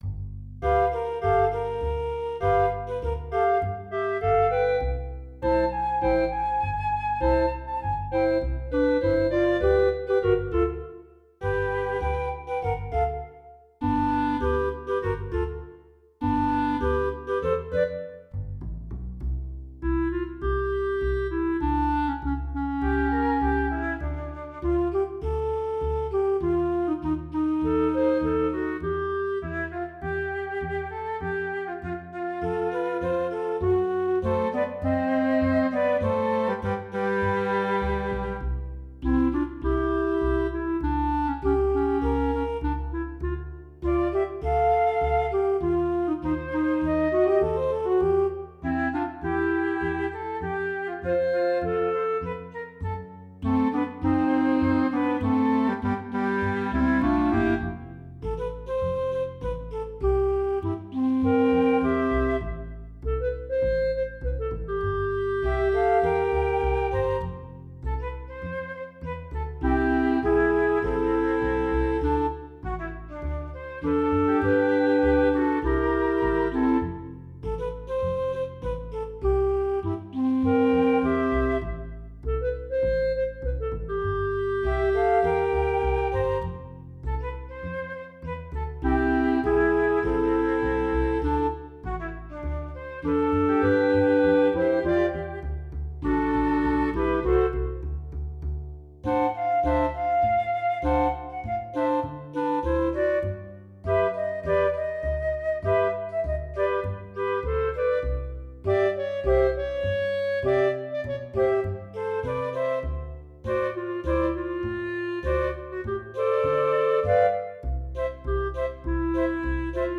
All the instrumental works are recorded through Finale: notation software that gives me the sound of virtually any instrument, from simple piano to a symphony orchestra, and astonishingly lifelike.
But I liked the music a great deal, with that chromatic ascending bass part. So I made an instrumental of it.
Flute, alto flute, clarinet, and string bass